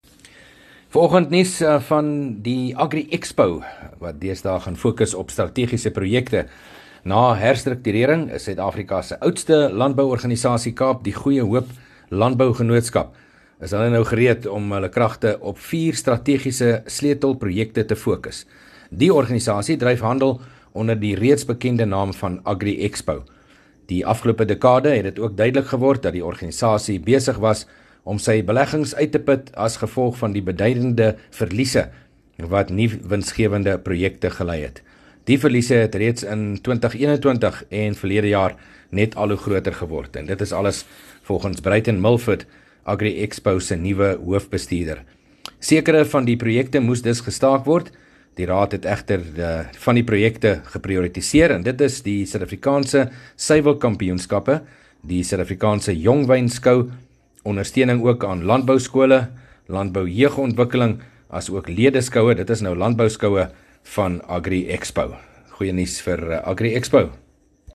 Landbou Insetsels